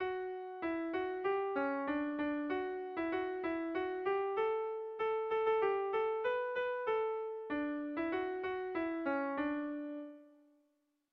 Erromantzea
Kopla ertaina
AB